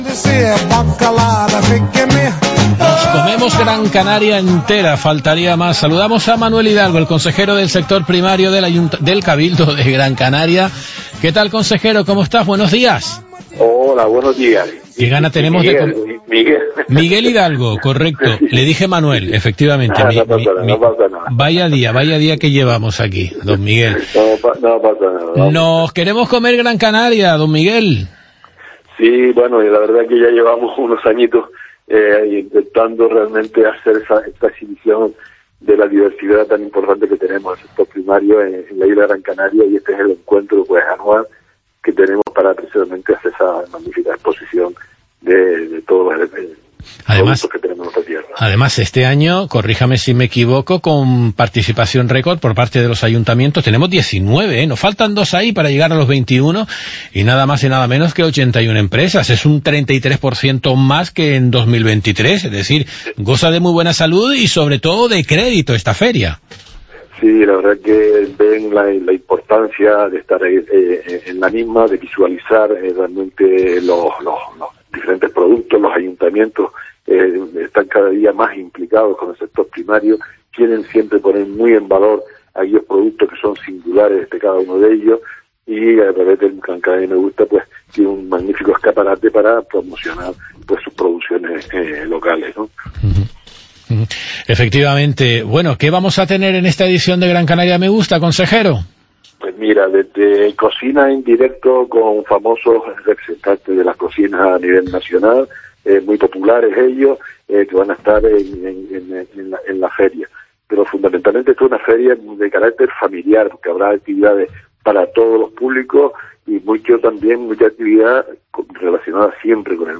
Miguel Hidalgo, consejero del Sector Primario, Soberanía Alimentaria y Seguridad Hídrica del cabildo